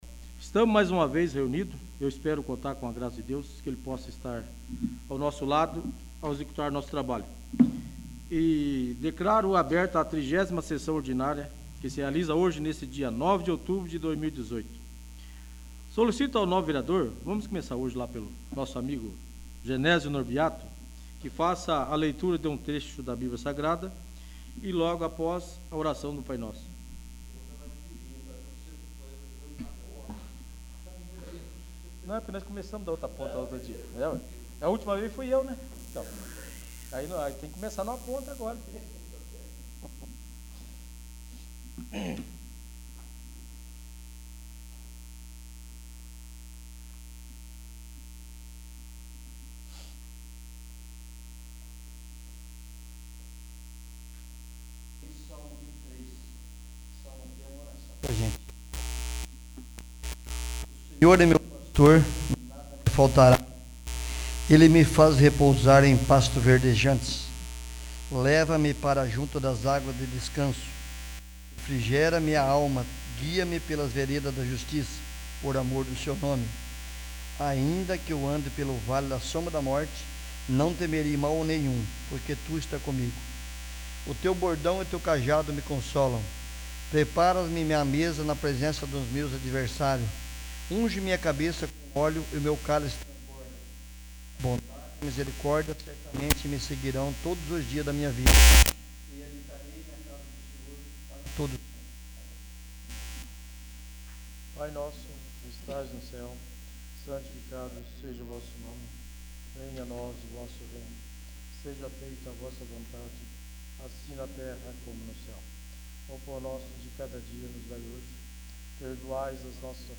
30º. Sessão Ordinária
| Ir para a navegação Ferramentas Pessoais Poder Legislativo Câmara de Vereadores do Município de Rio Bom - PR Mapa do Site Acessibilidade Contato VLibras Contraste Acessar Busca Busca Avançada…